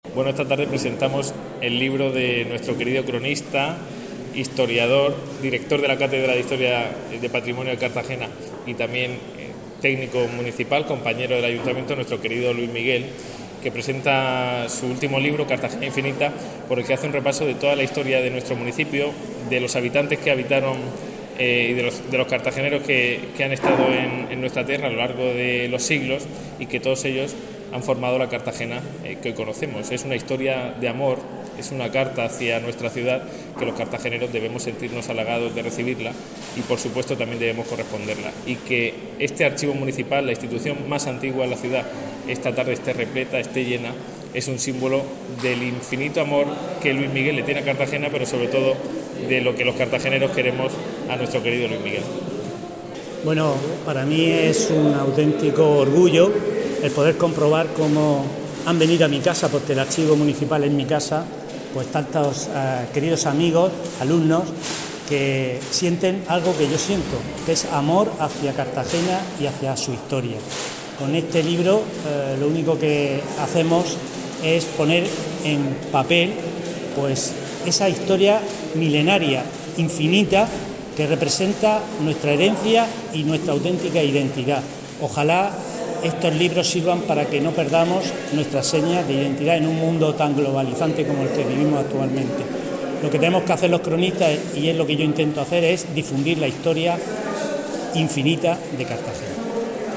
Declaraciones
ha presentado su obra en el Archivo Municipal